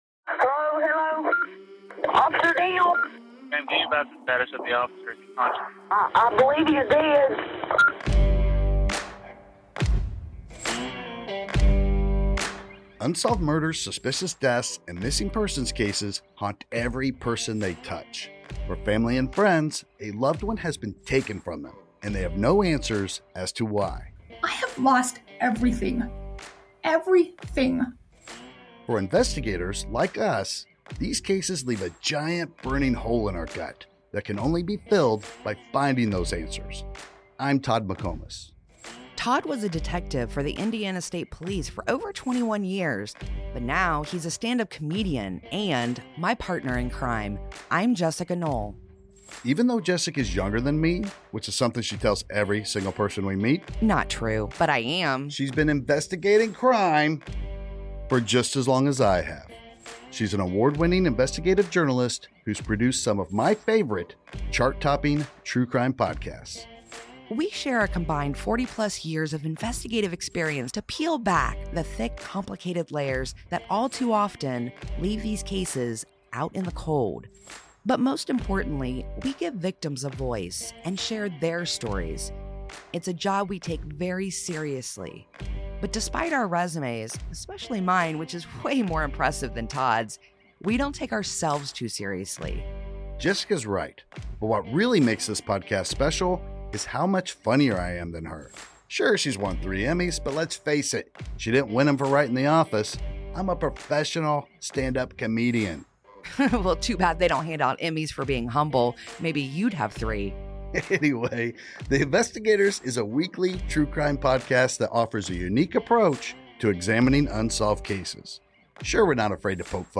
Trailer: The Investigators
But, because they don't take themselves seriously, listeners get the expert analysis and commentary they should expect, mixed with enough witty banter to feel like they're among friends.